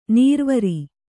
♪ nīrvari